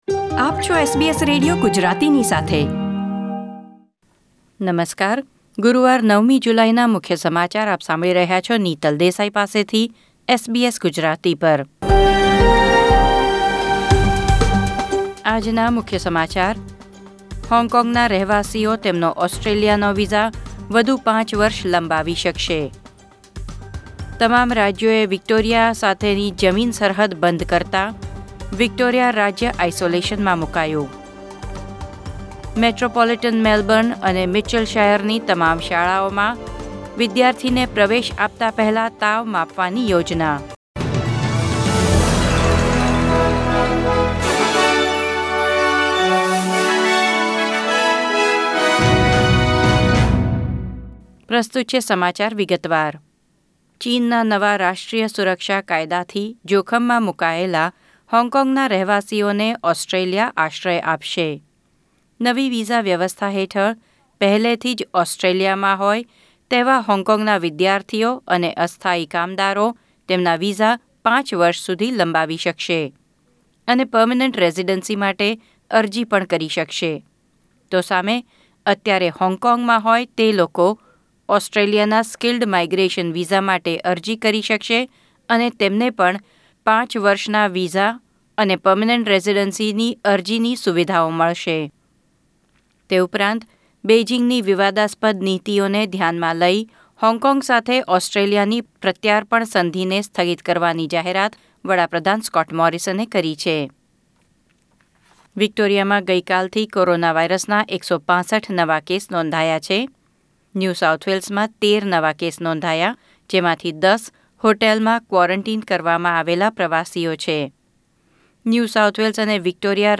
SBS Gujarati News Bulletin 9 July 2020